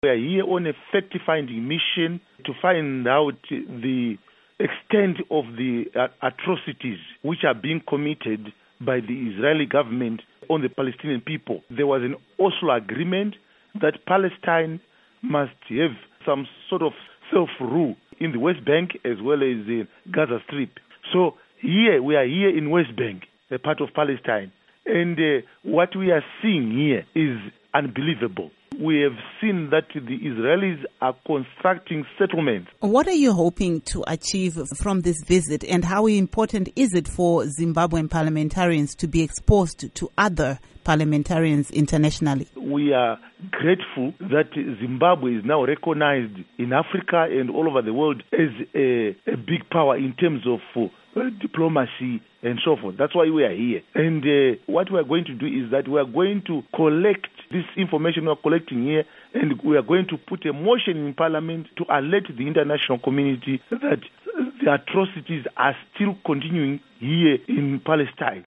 Itnerview with Kindness Paradza